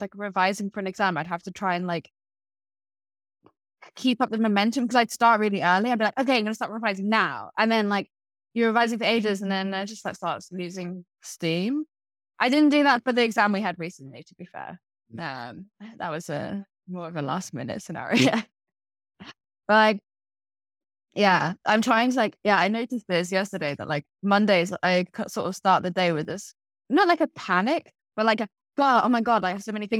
conversational_a.wav